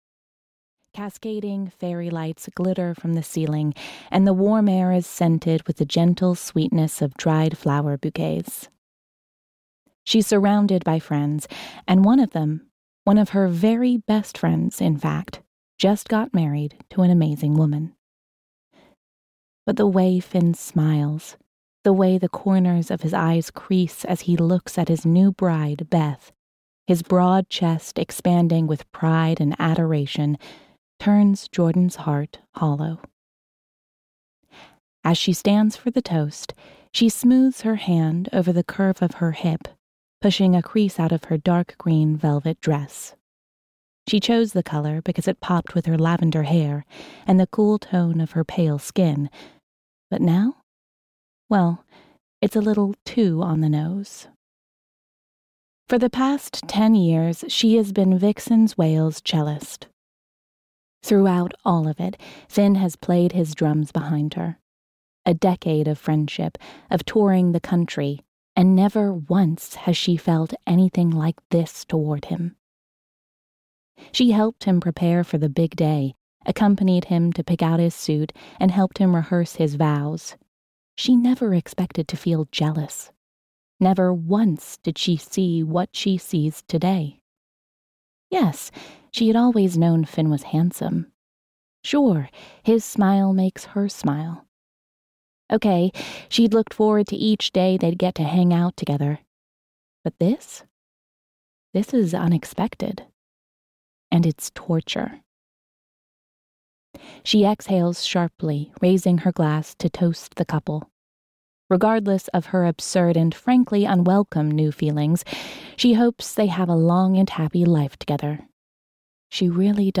Audio knihaStrings (EN)
Ukázka z knihy